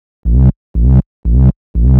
TSNRG2 Off Bass 020.wav